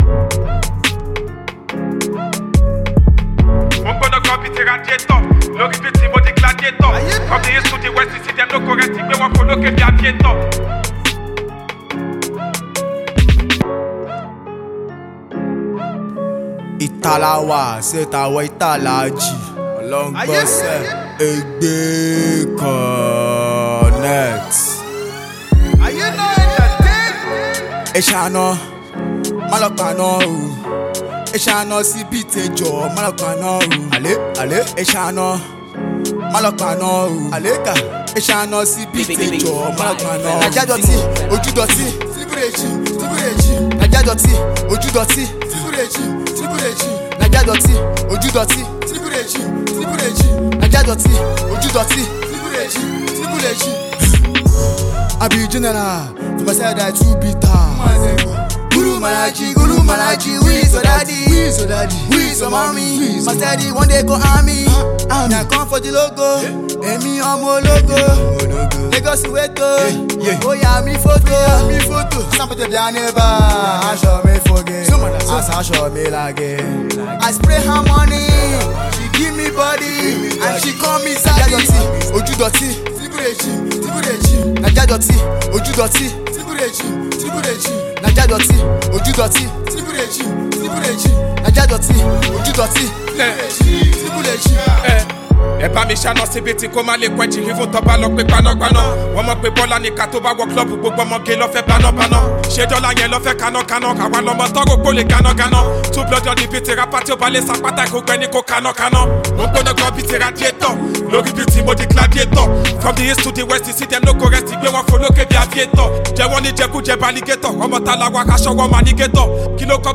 blend smooth beats with compelling verses